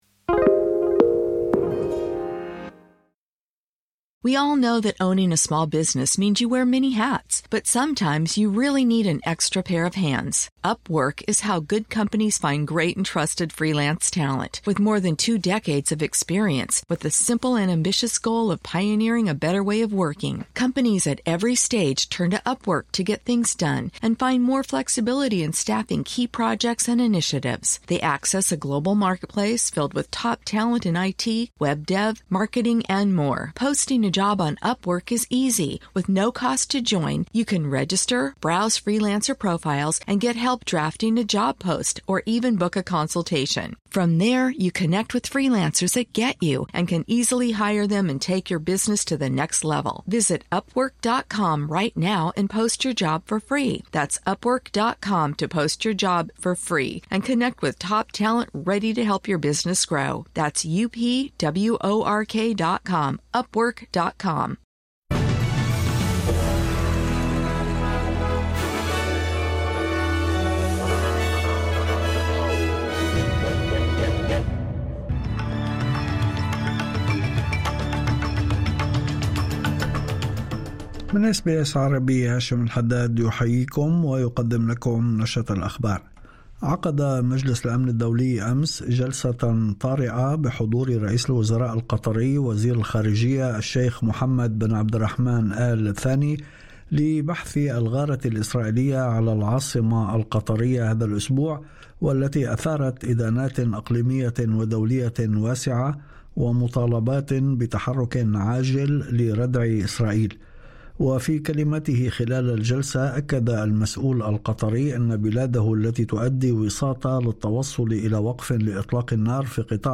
نشرة أخبار الظهيرة 12/09/2025